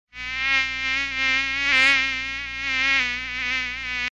Play, download and share FFly Sound Effect original sound button!!!!
fly_01.mp3